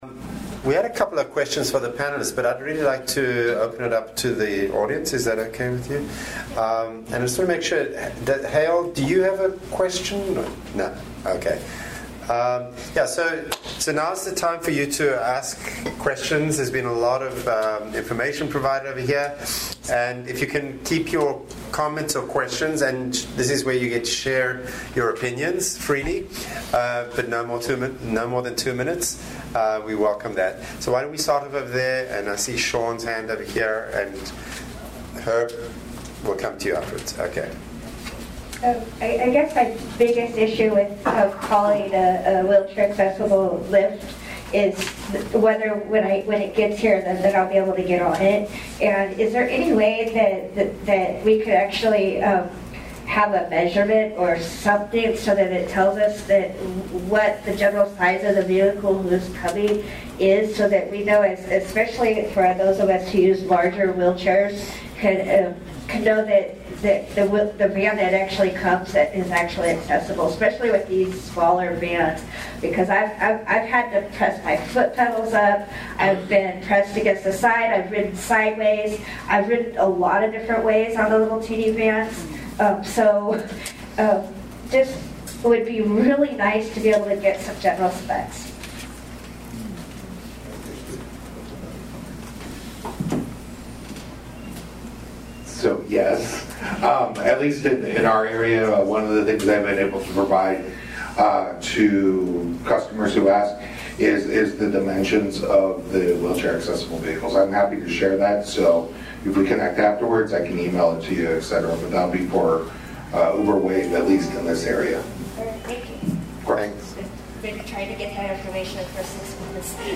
Joint PAPCO and ParaTAC Meeting - Alameda CTC
Location Alameda CTC Offices 1111 Broadway, Suite 800 Oakland, California, 94607